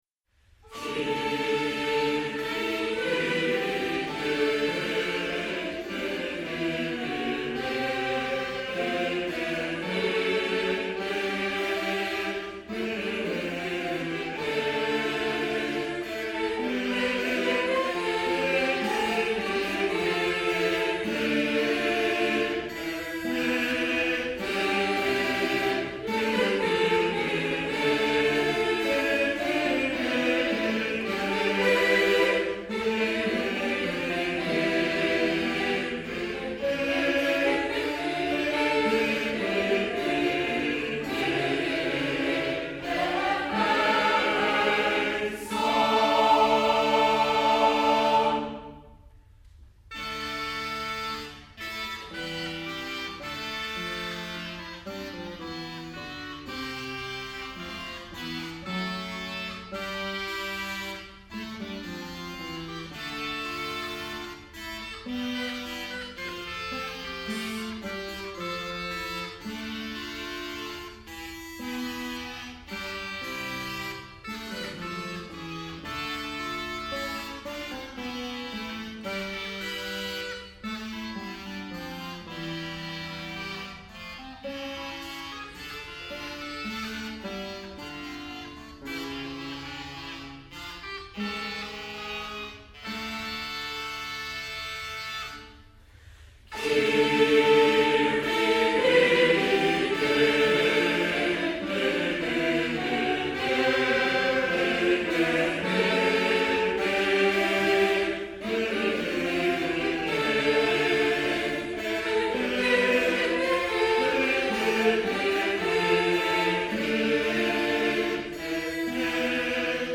Written by Guillaume de Machautr, Berdorf Vocal Ensemble